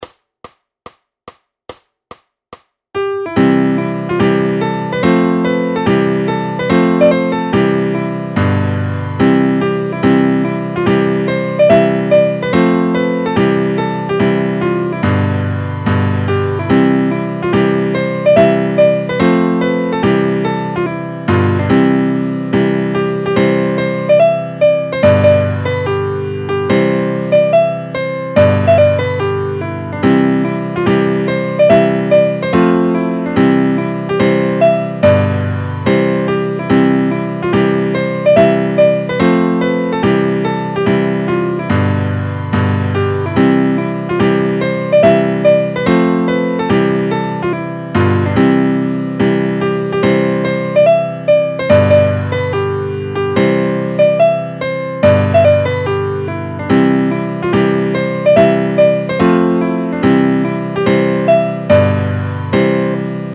Noty na snadný klavír.
Formát Klavírní album
Hudební žánr Irská lidová hudba